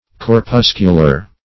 Corpuscular \Cor*pus"cu*lar\ (k?r-p?s"k?-l?r), a. [Cf. F.